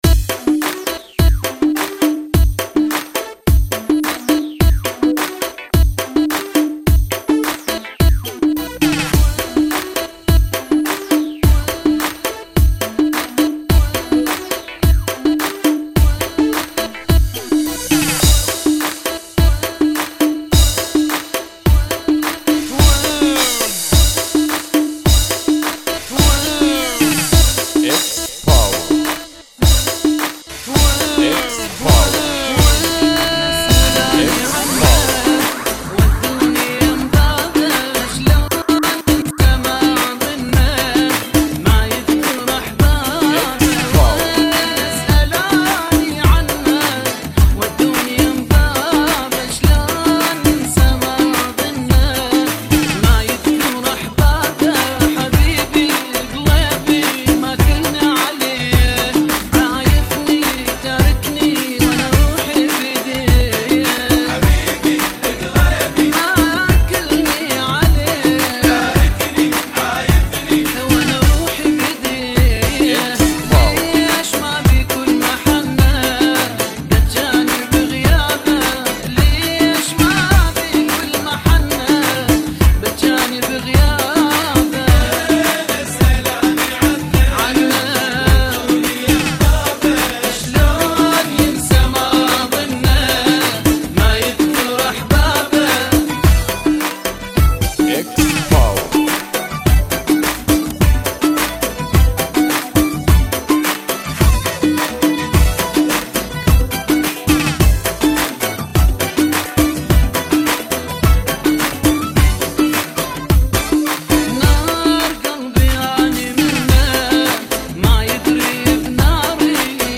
[ 106 bpm ]